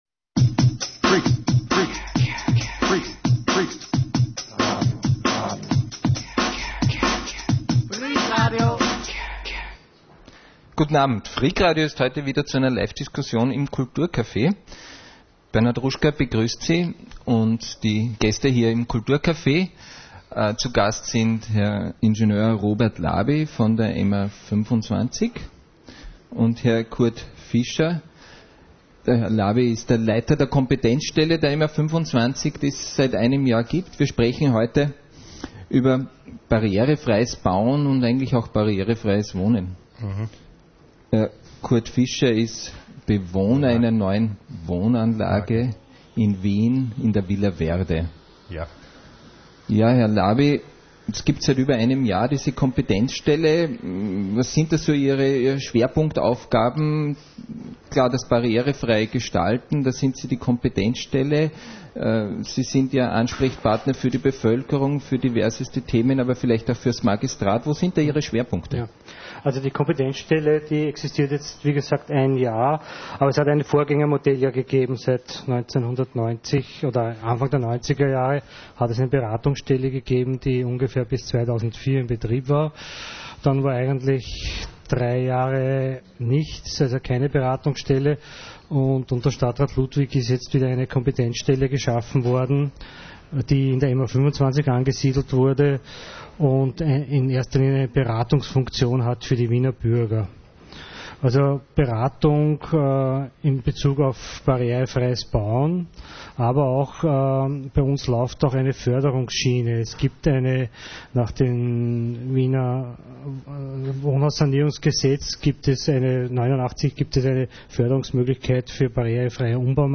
Freak Online - die Webplattform von Freak Radio, dem Radio über den barrierefreien Lifestyle.